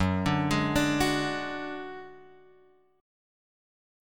F#sus2#5 chord {2 x 0 1 3 2} chord